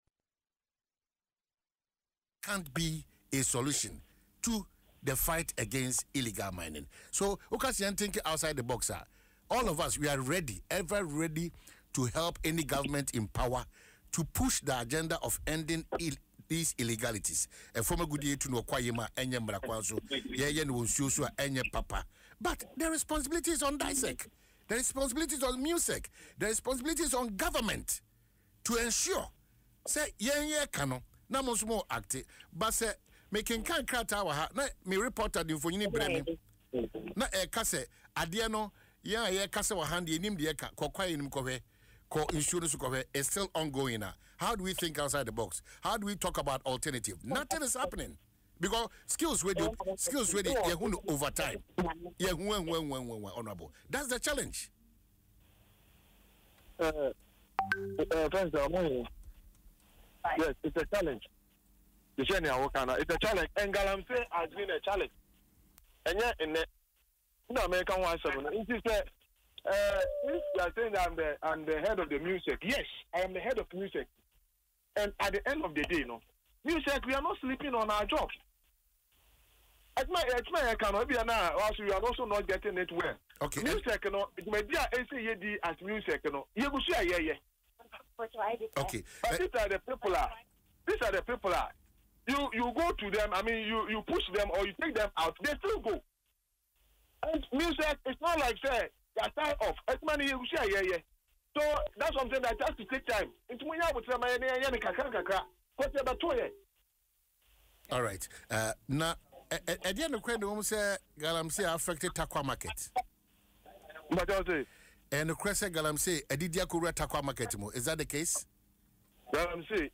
However, speaking on Adom FM’s Dwaso Nsem, Mr. Cobbinah clarified that the incident was not caused by illegal mining but rather by underground mining that has taken place in the area since the Gold Coast era.